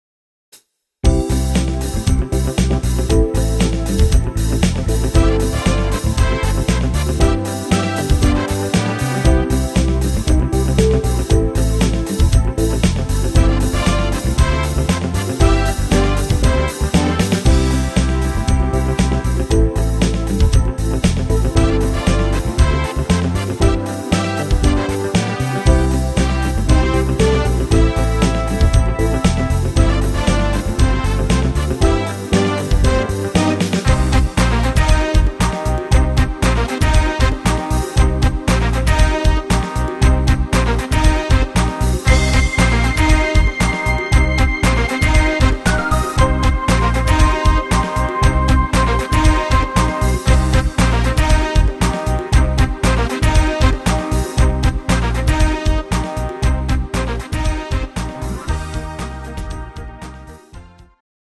instr. Orchester
Rhythmus  70-er Disco
Art  Instrumental Orchester, Oldies